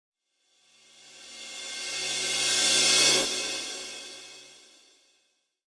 Reverse cymbal.mp3 📥 (1.11 MB)